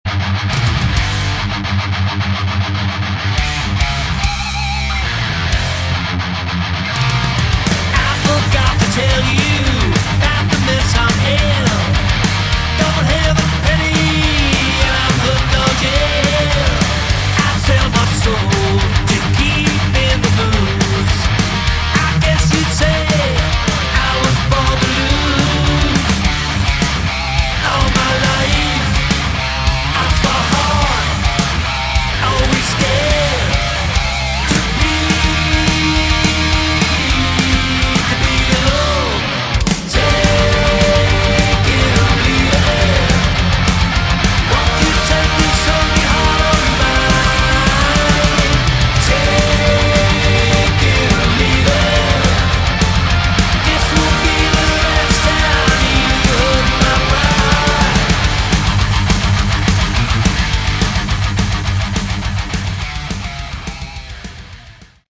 Category: Melodic Metal
vocals
bass